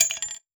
weapon_ammo_drop_11.wav